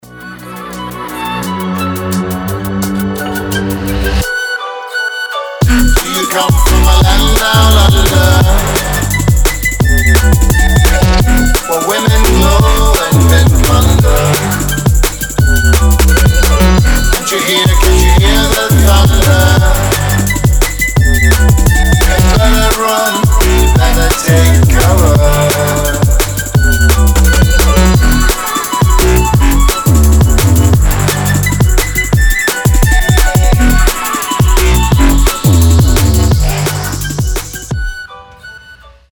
• Качество: 320, Stereo
громкие
Флейта
ремиксы
драм энд бейс